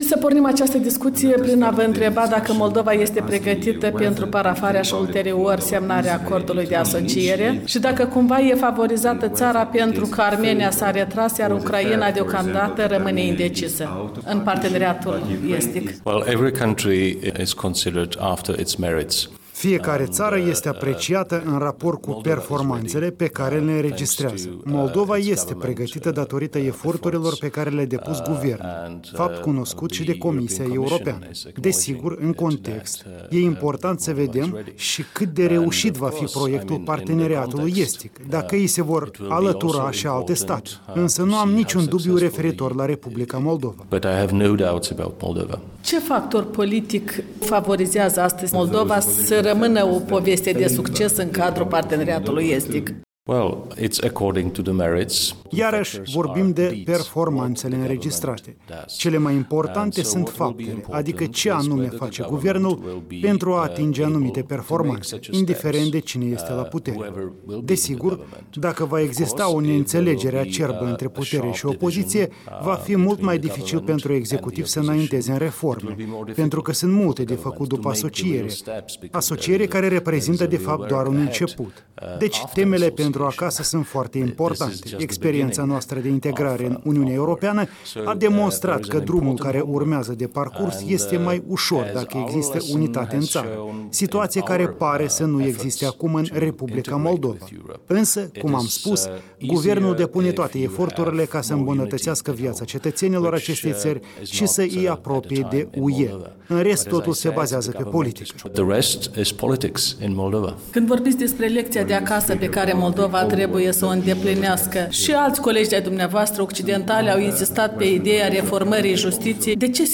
Interviu cu Jiří Schneider, prim-viceministru al Afacerilor Externe al Republicii Cehe